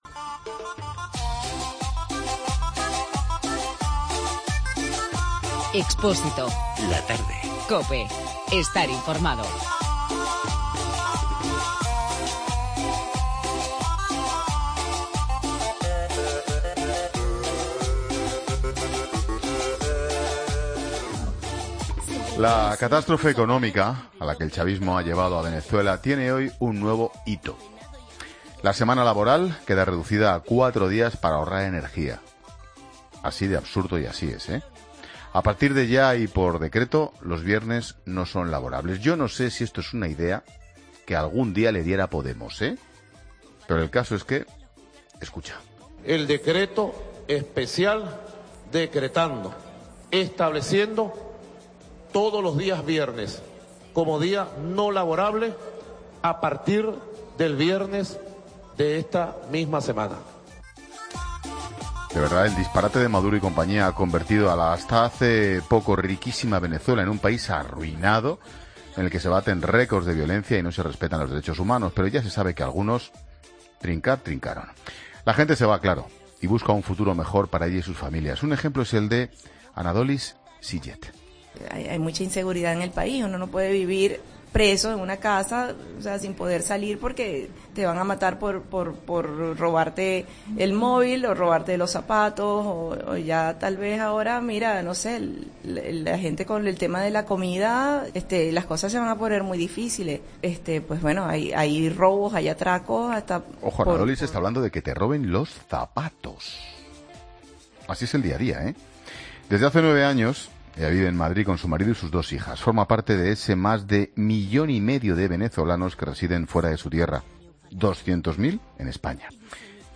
Reportaje